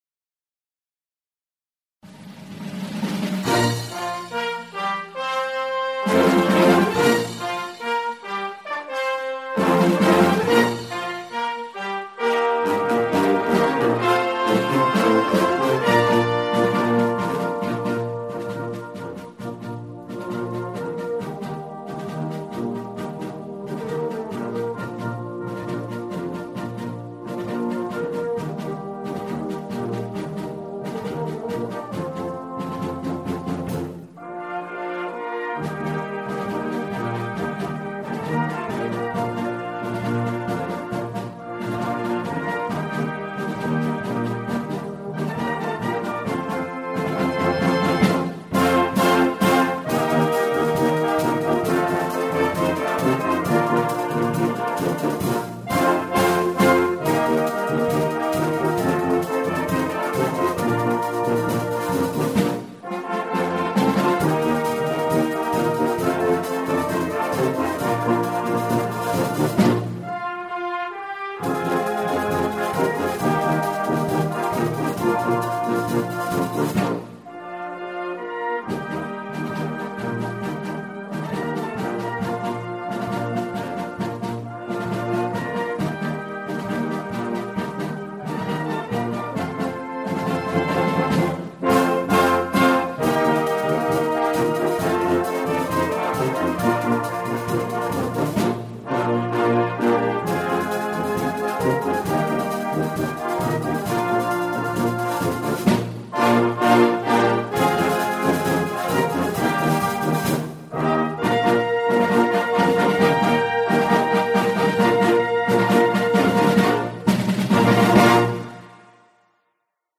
brass-in-the-bay